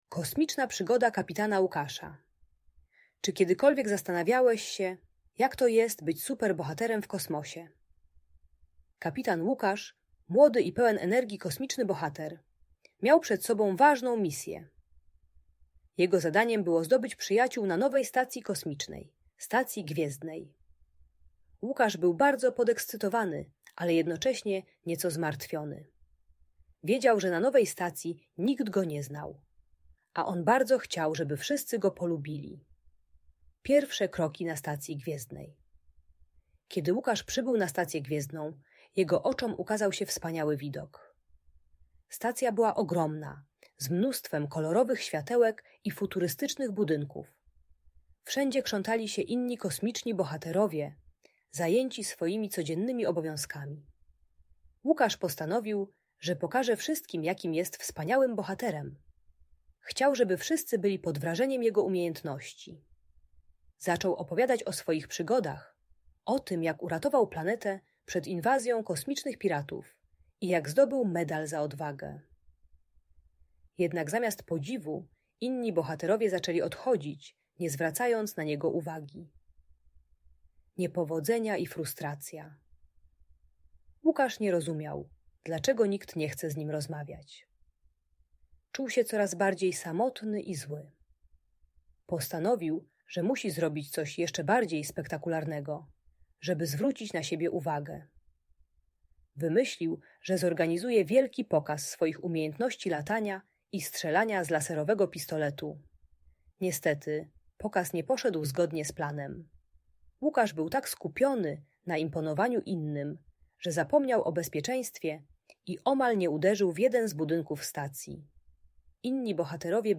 Kosmiczna Przygoda Kapitana Łukasza - Bunt i wybuchy złości | Audiobajka